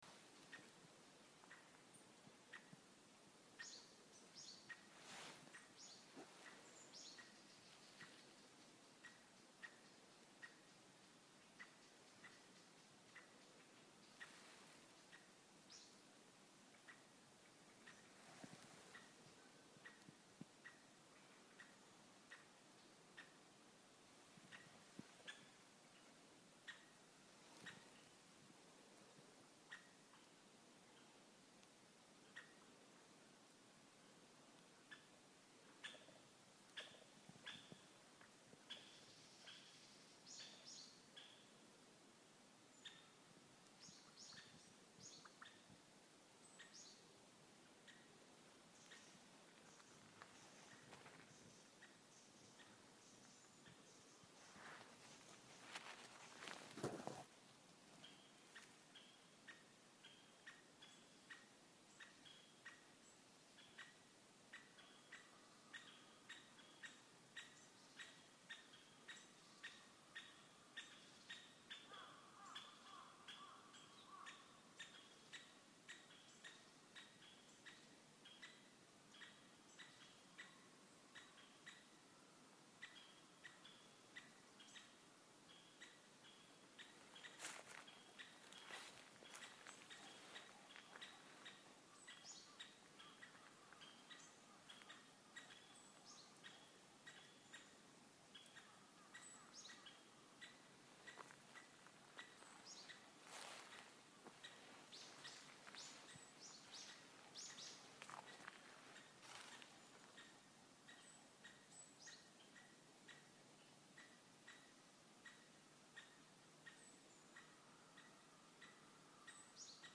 アカゲラ
夕方、日没直前に小さな沢沿いを歩いていると頭上にアカゲラの声。鳴きながらこちらに近づいてきます。わたしのほぼ真上の樹で枝から枝へ飛び移りながらしきりに鳴いています。そのとき、声の合間にポポポポという音が。羽ばたきの音のようです。
25秒ぐらいから近づいてきて、35秒ぐらいに羽音が入っています。
その後離れた場所で別の鳥が鳴き始めると、テンション上がってピッチが速くなりました。いつまでも鳴き交わす２羽。